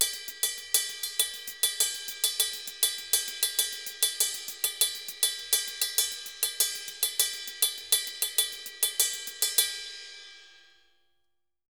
Ride_Candombe 100_2.wav